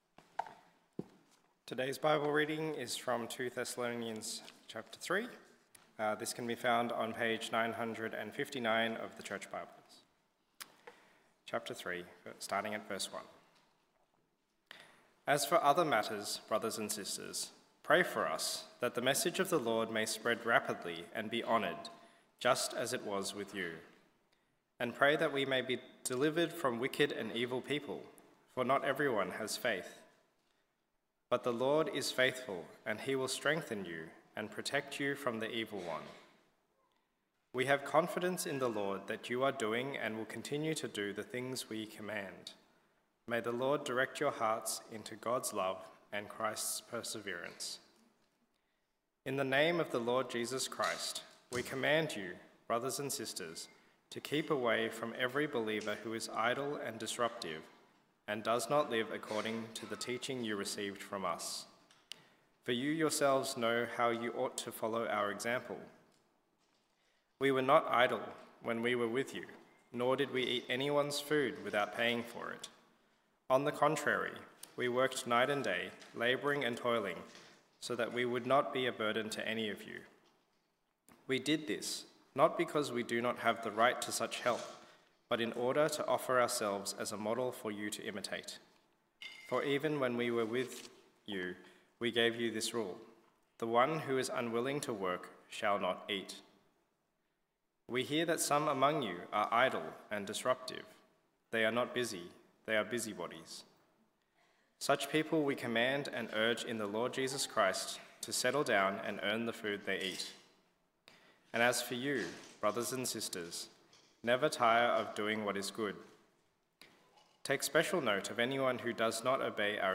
1 Sunday Morning Service Sermon 40:07